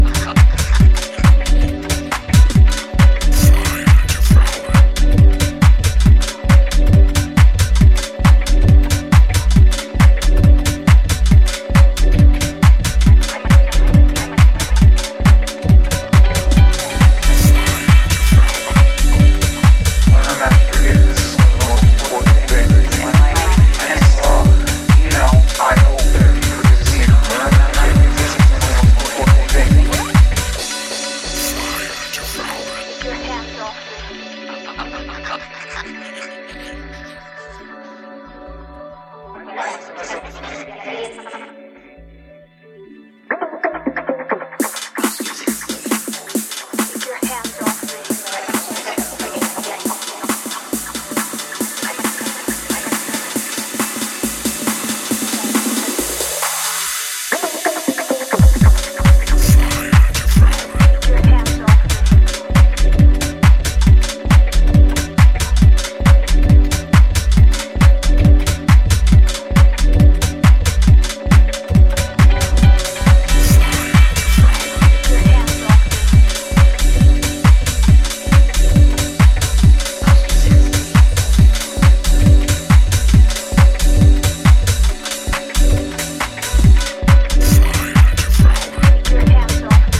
One of the UK’s most exciting house talents
hazy and energetic take on the title cut